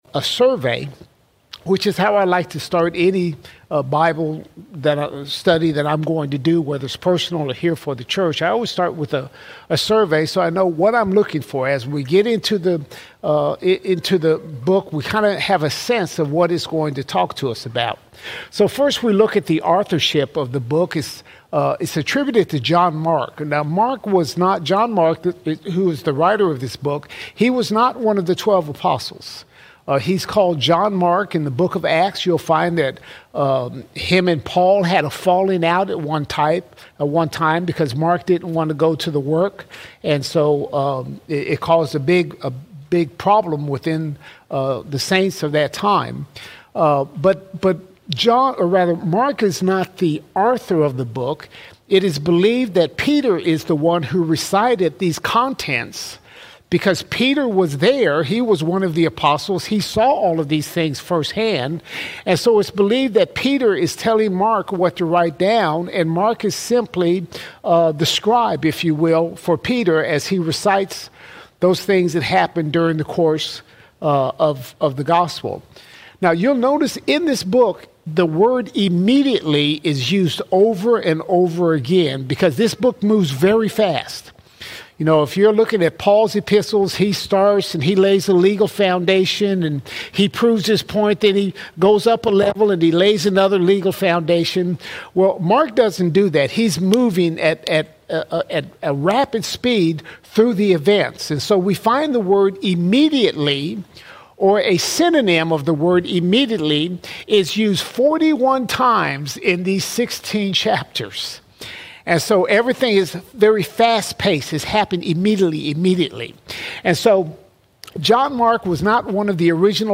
2 October 2025 Series: Gospel of Mark All Sermons Mark 1:1 - 1:21 Mark 1:1 – 1:21 Discover the beginning of Mark’s Gospel as we explore Mark 1:1–21.